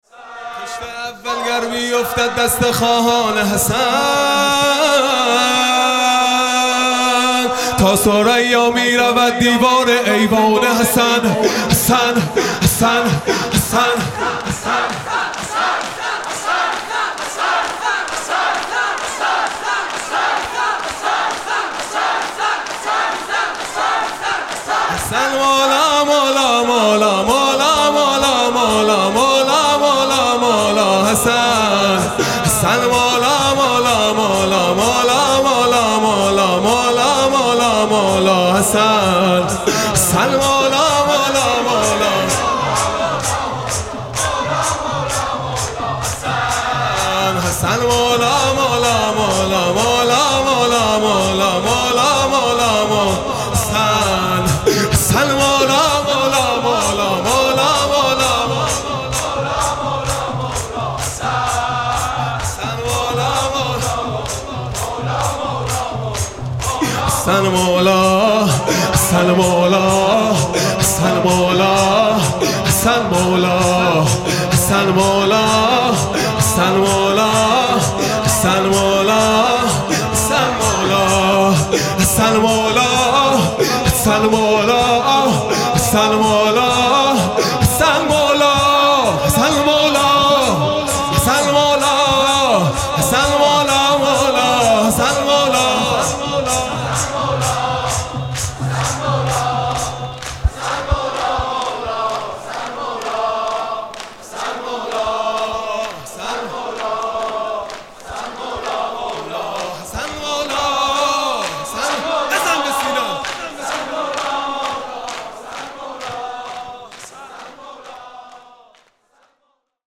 خیمه گاه - هیئت بچه های فاطمه (س) - شور | خشت اول گر بیوفتد دست خواهان حسن | 1 مردادماه 1402